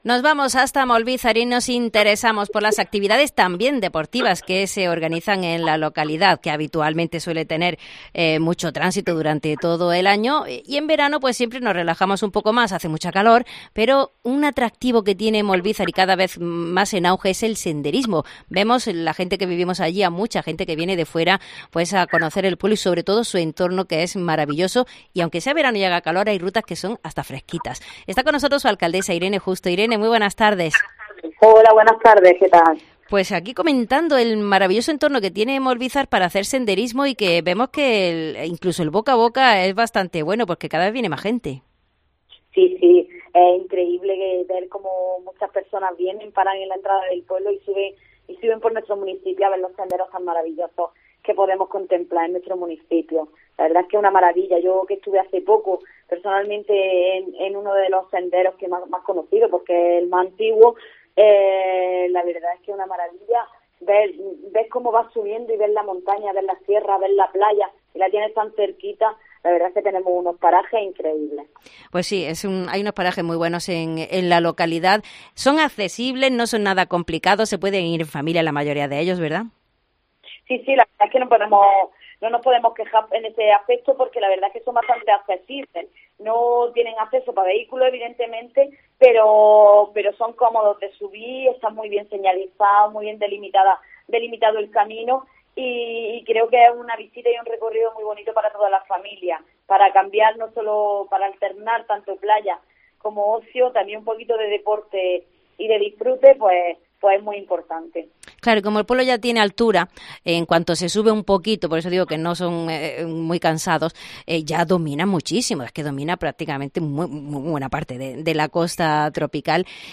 La alcaldesa de Molvízar, Irene Justo nos comenta como cada vez está más en auge practicar senderismo en el extraordinario entorno de la localidad, donde existen varias rutas, que se pueden practicar en familia sin ningún problema.